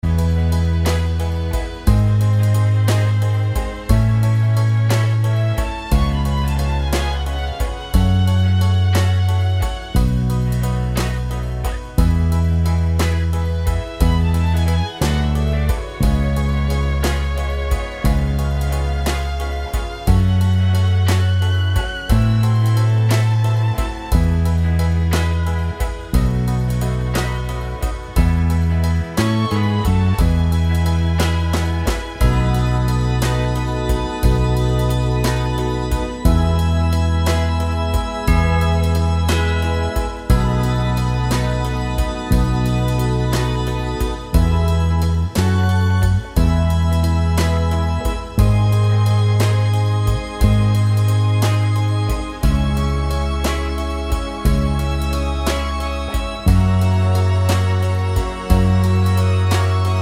Seven Semitones Down Pop (1950s) 2:22 Buy £1.50